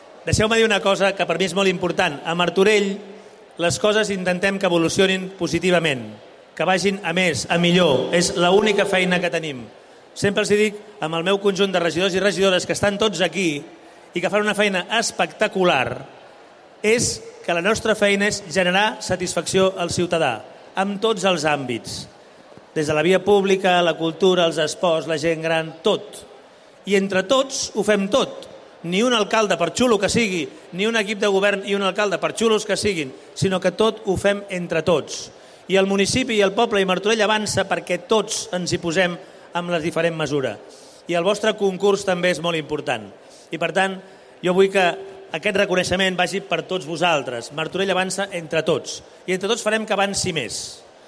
Xavier Fonollosa, alcalde de Martorell
Parlaments-Alcalde-Trobada-Tardor-Gent-Gran-02.mp3